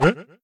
confusedanswer.wav